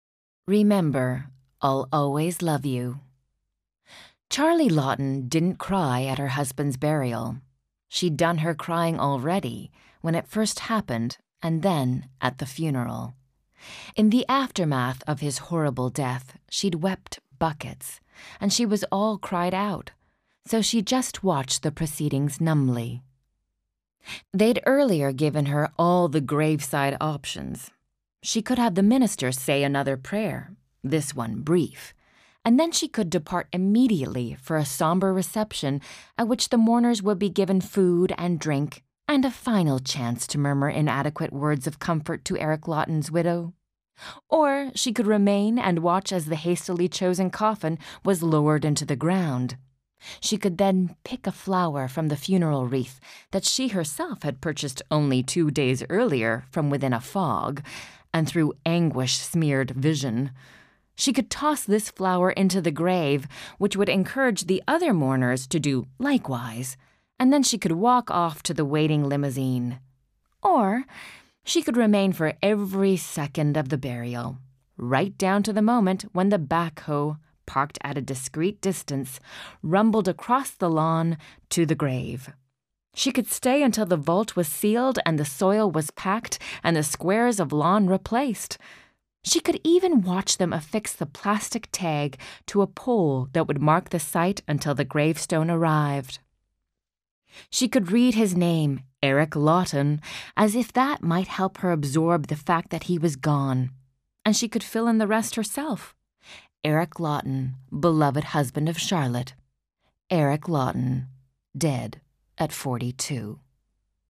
Das Hörbuch zum Sprachen lernen.Ungekürzte Originalfassung / 2 Audio-CDs + Textbuch + CD-ROM
Interaktives Hörbuch Englisch